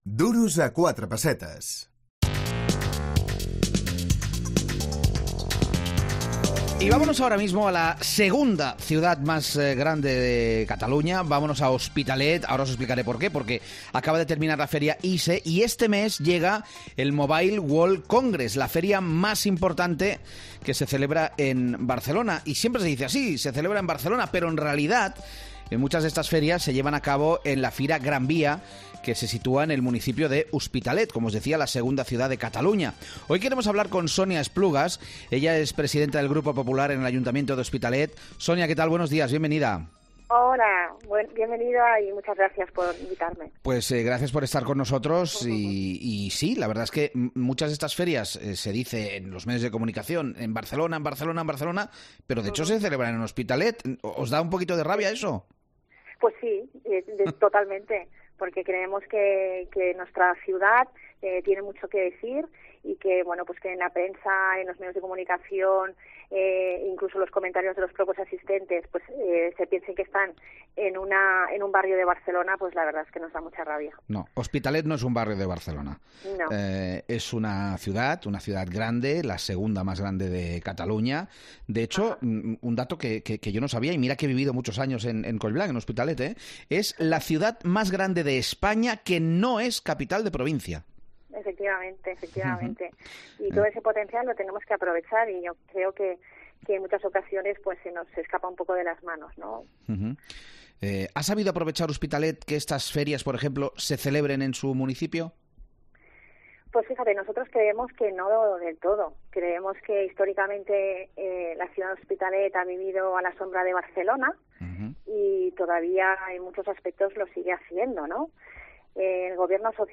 Entrevista a Sonia Esplugues, portavoz del PP en Hospitalet del Llobregat